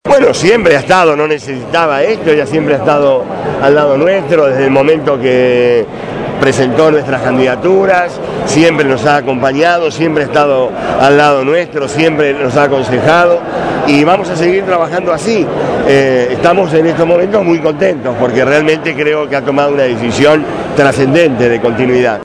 registró para los micrófonos de Radio Gráfica FM 89.3 los testimonios de la jornada.